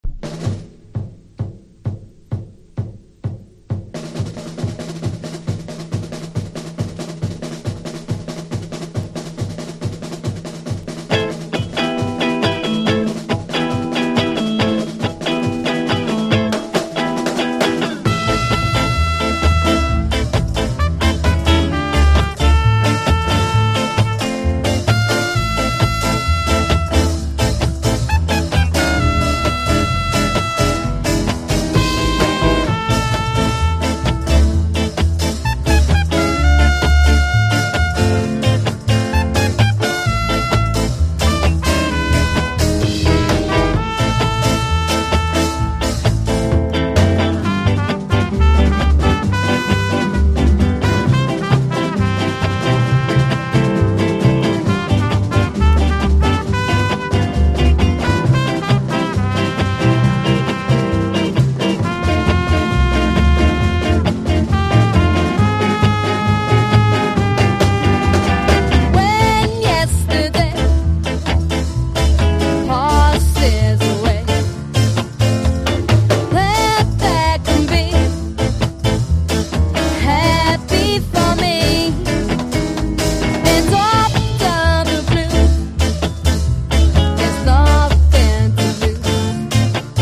# OLDIES / BLUES# GARAGE ROCK# 60’s ROCK